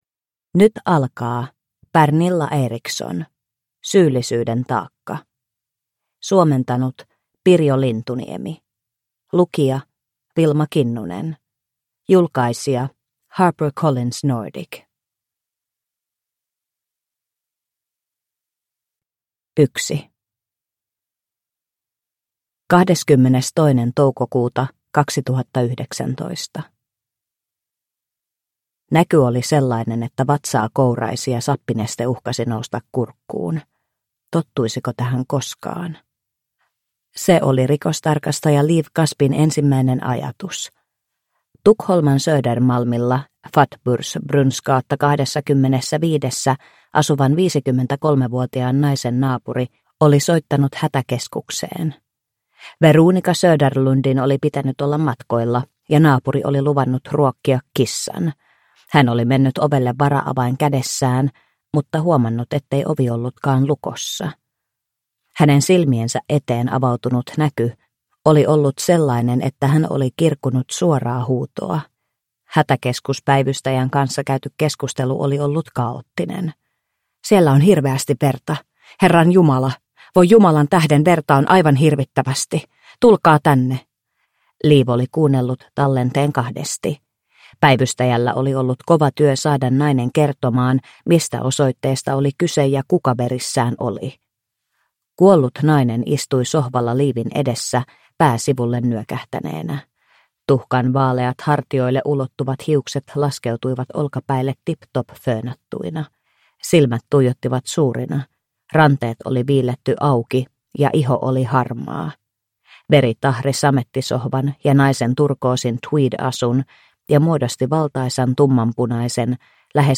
Syyllisyyden taakka – Ljudbok – Laddas ner